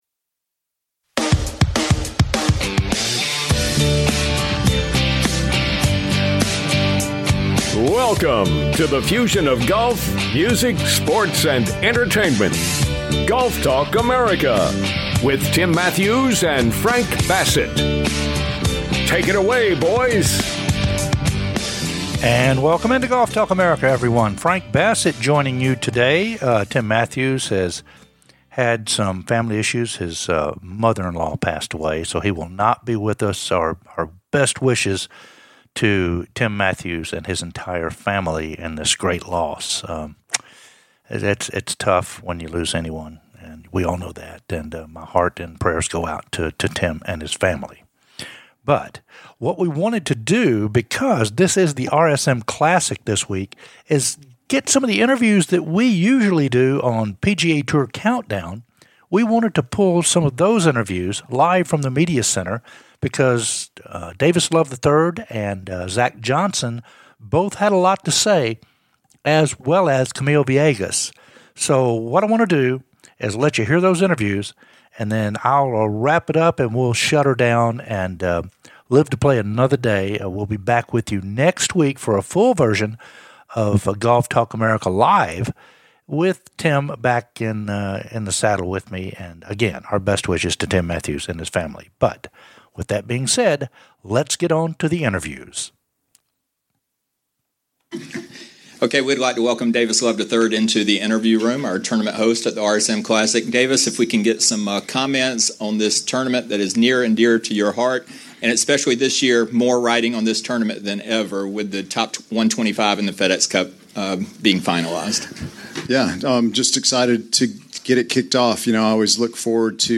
"LIVE" INTERVIEWS FROM THE RSM CLASSIC AT SEA ISLAND
From The Media Center at the RSM Classic